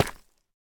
Minecraft Version Minecraft Version 1.21.5 Latest Release | Latest Snapshot 1.21.5 / assets / minecraft / sounds / block / deepslate / step4.ogg Compare With Compare With Latest Release | Latest Snapshot
step4.ogg